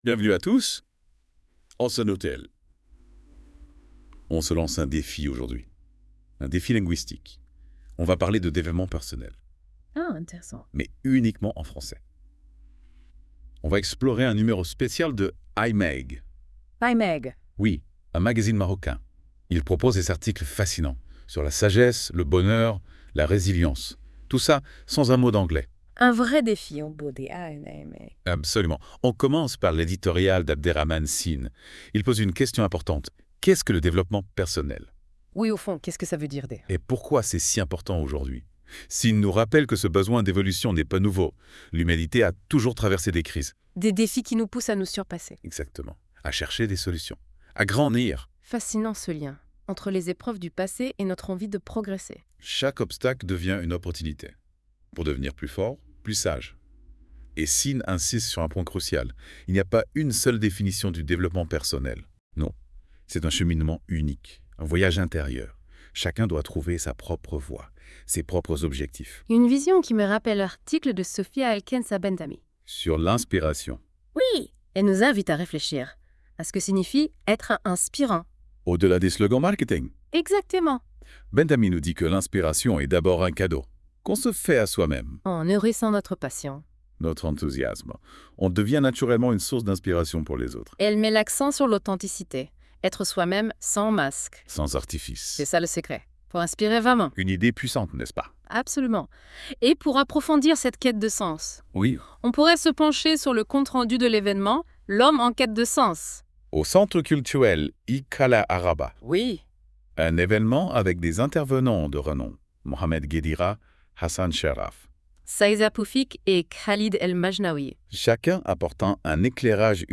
Podcast-Débat I-MAG Spécial - Développement Personnel.wav (35.24 Mo)
Les chroniqueurs de la Web Radio R212 ont lus attentivement ce I-MAG Spécial Stress Hydrique de L'ODJ Média et ils en ont débattu dans ce podcast